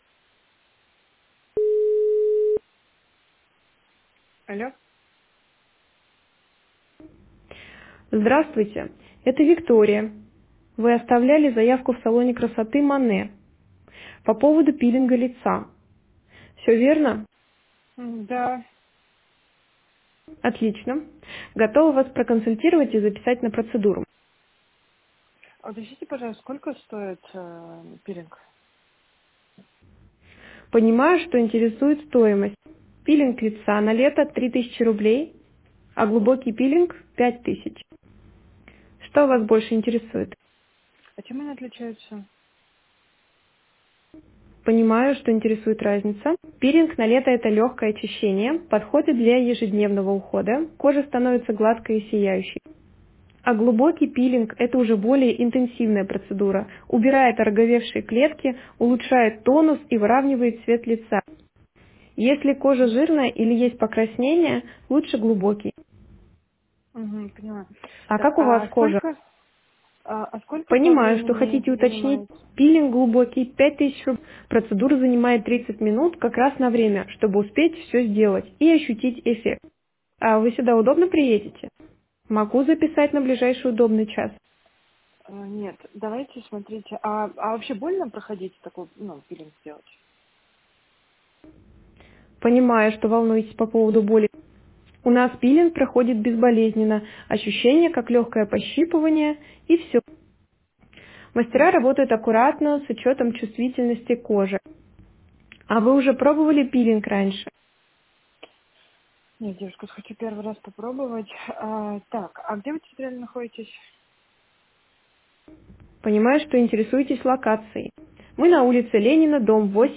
“AI-звонок”
• Очень реалистичный голос
голос Виктория - запись в салон красоты (пилинг лица)